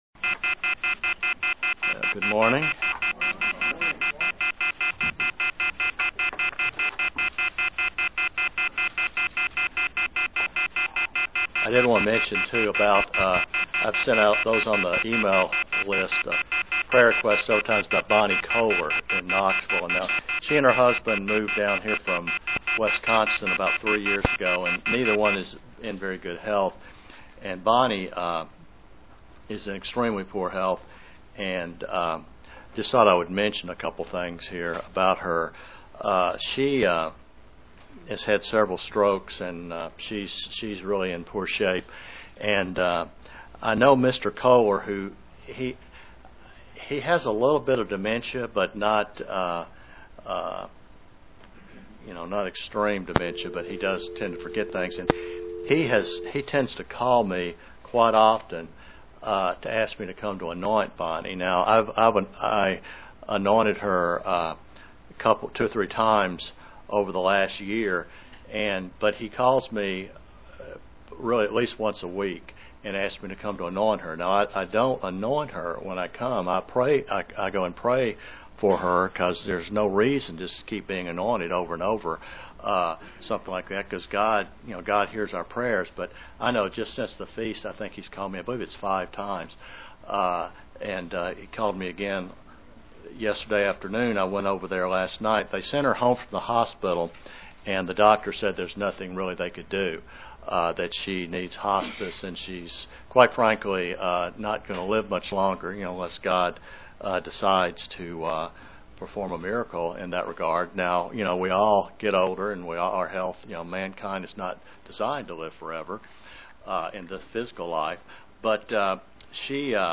Given in London, KY
UCG Sermon Studying the bible?